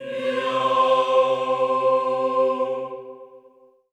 HEE-AH  C3-R.wav